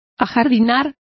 Complete with pronunciation of the translation of landscape.